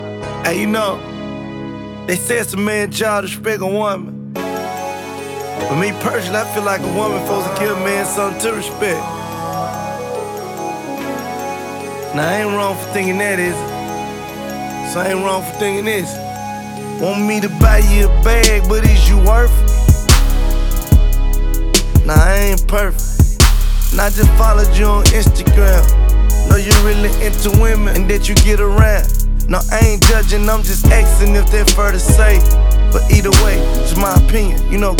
Hip-Hop Rap Rap Dirty South Hardcore Rap
Жанр: Хип-Хоп / Рэп